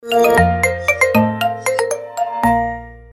Короткие рингтоны
Рингтоны на смс и уведомления
Рингтоны со словами